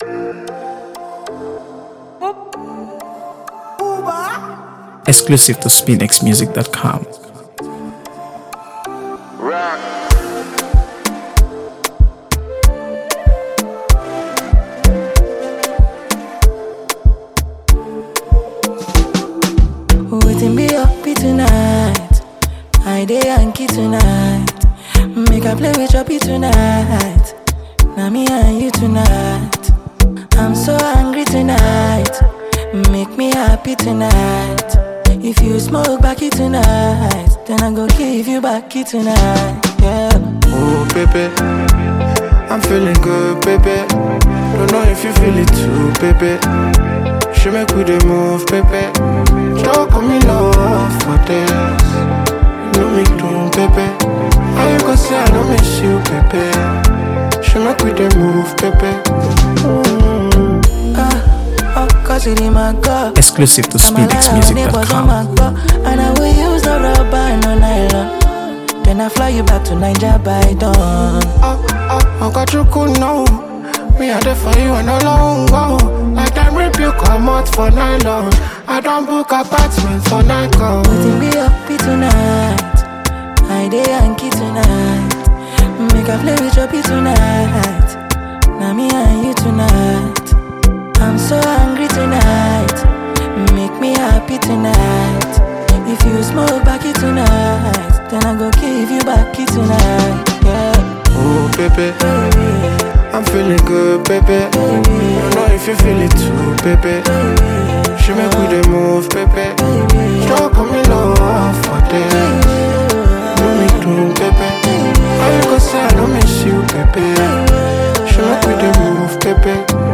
AfroBeats | AfroBeats songs
a celebrated Nigerian singer-songwriter and performer.